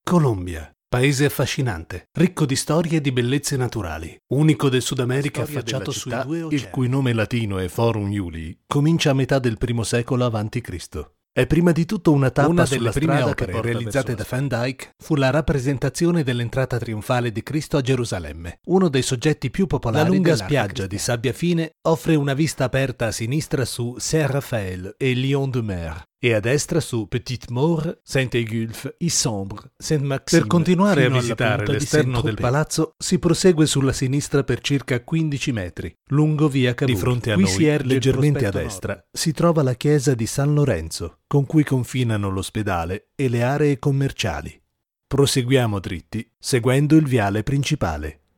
Male
Adult (30-50), Older Sound (50+)
Tour Guide
audioguide_demoreel
0923Audioguide_DemoReel.mp3